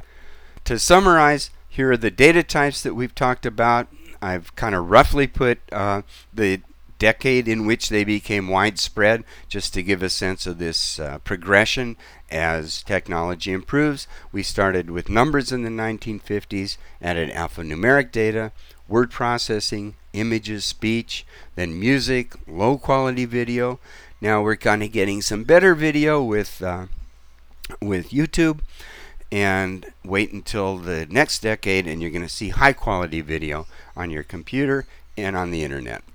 and recorded some narration using Audacity. The result was
I was not trying to make a professional recording, but when I listened to it, I heard significant problems: